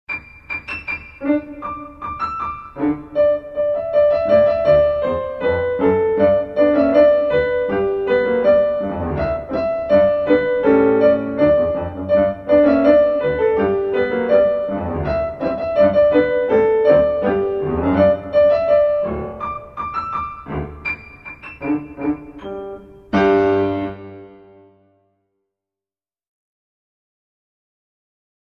Solo